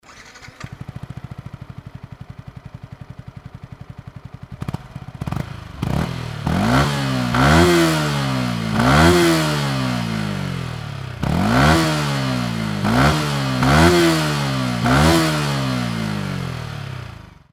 それでは排気音を収録してきたので
YZF-R125純正マフラー
yzf-r125_re45j-normal.mp3